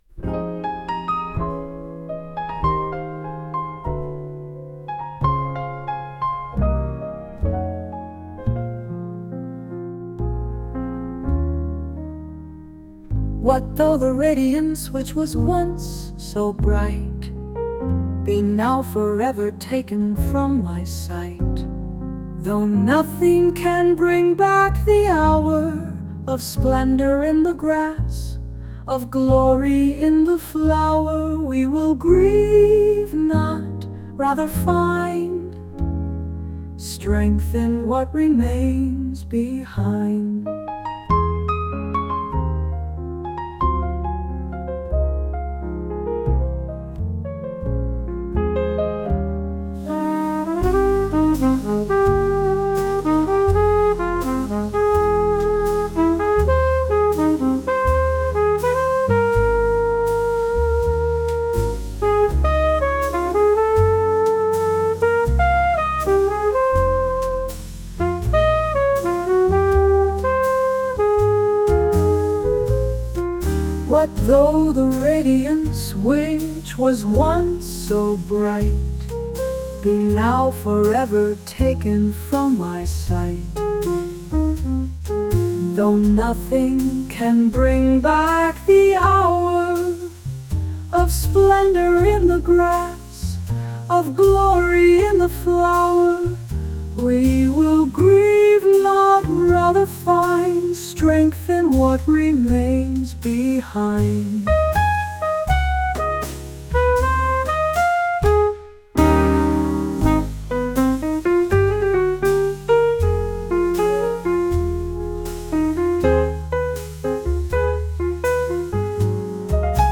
５月１９日　AI による作曲・演奏